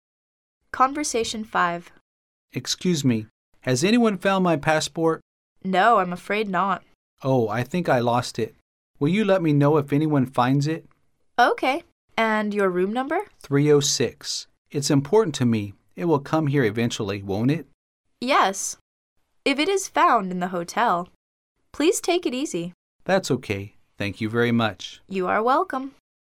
Conversation 5